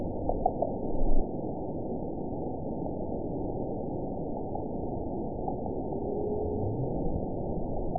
event 922206 date 12/28/24 time 07:03:46 GMT (5 months, 2 weeks ago) score 9.25 location TSS-AB03 detected by nrw target species NRW annotations +NRW Spectrogram: Frequency (kHz) vs. Time (s) audio not available .wav